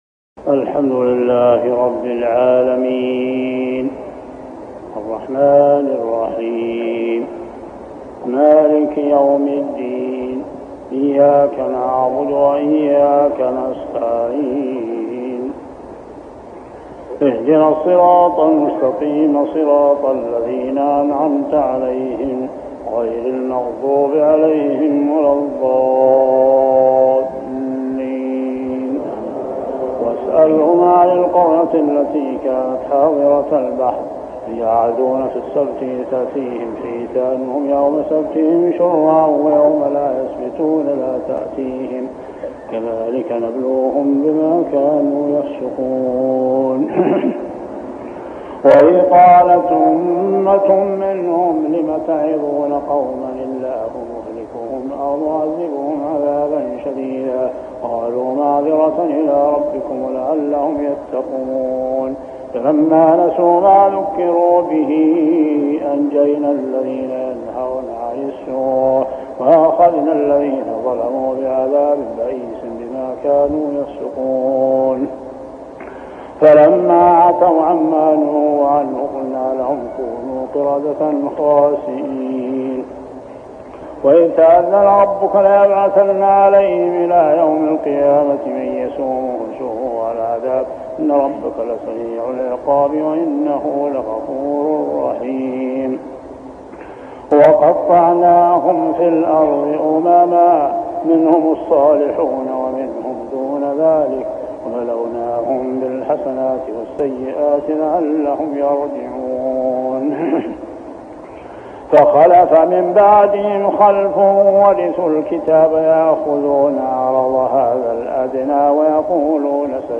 صلاة التراويح عام 1403هـ سورة الأعراف 163-171 | Tarawih prayer Surah Al-A'raf > تراويح الحرم المكي عام 1403 🕋 > التراويح - تلاوات الحرمين